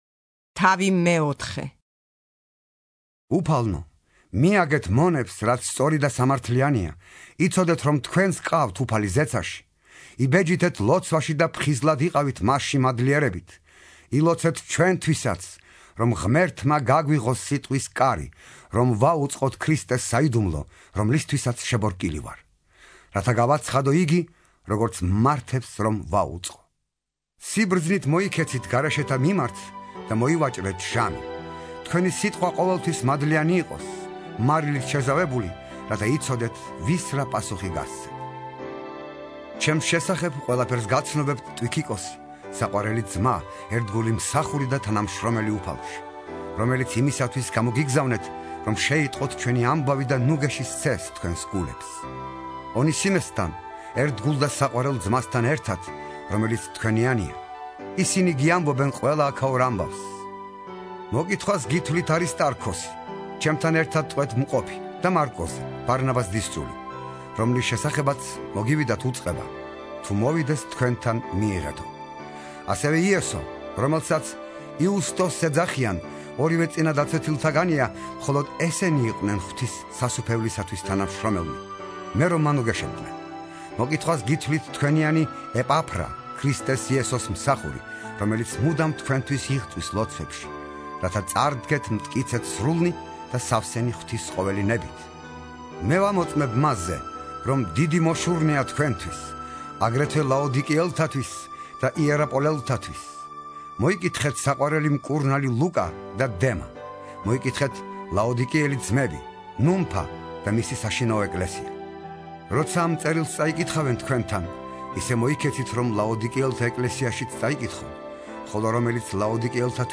(51) ინსცენირებული ახალი აღთქმა - პავლეს ეპისტოლენი - კოლასელთა მიმართ